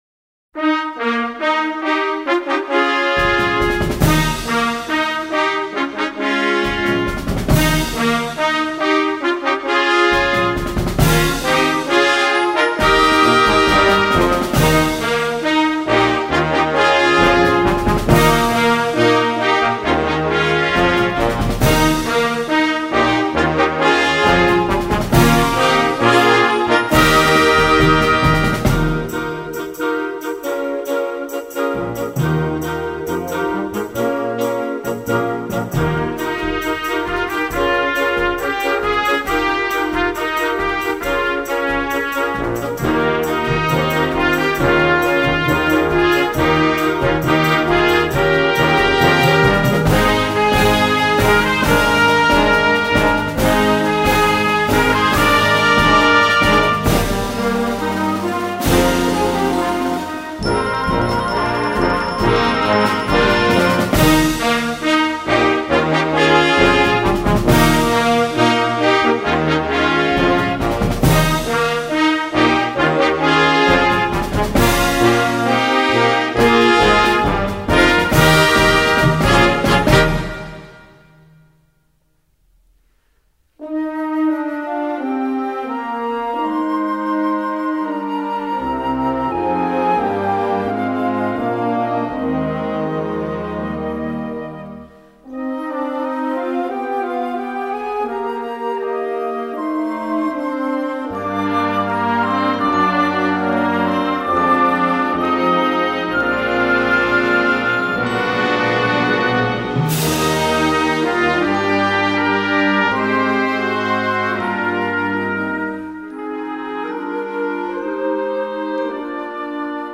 Partitions pour orchestre d'harmonie.
• View File Orchestre d'Harmonie